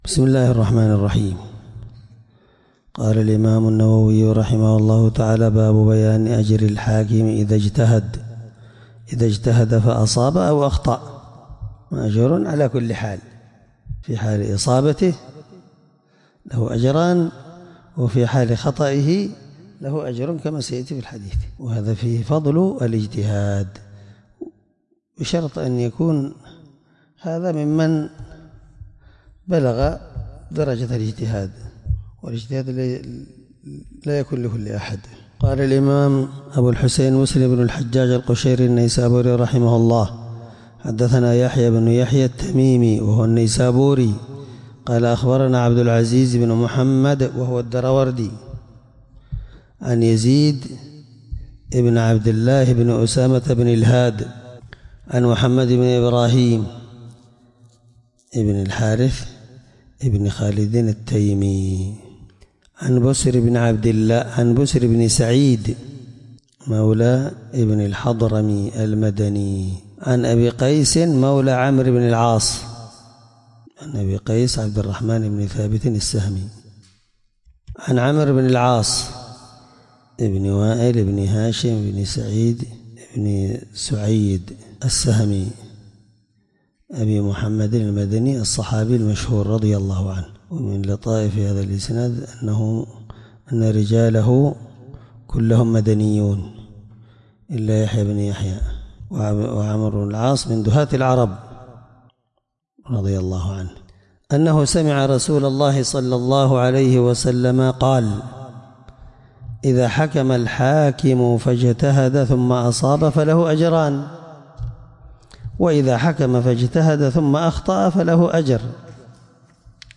الدرس7من شرح كتاب الأقضية الحدود حديث رقم(1716) من صحيح مسلم